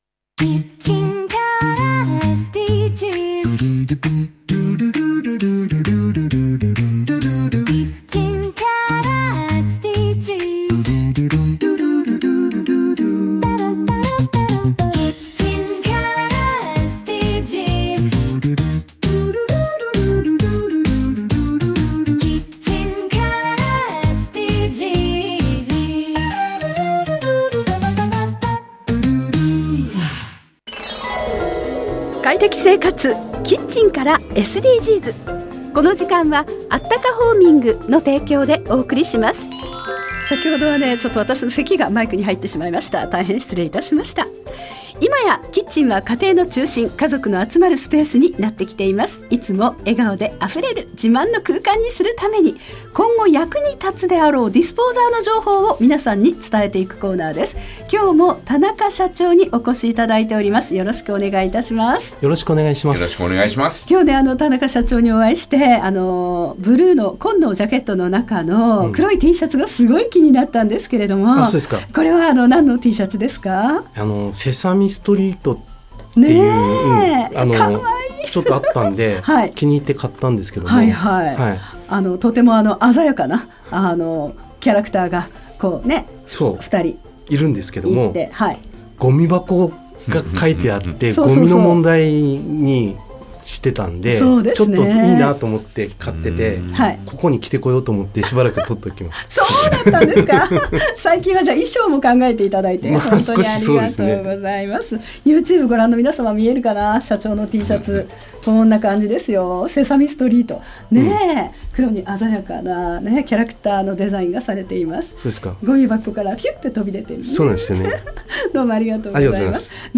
【ラジオ】快適生活 キッチンからSDGs 放送中 （ じゃらら（JAGA）10時30分～45分 ）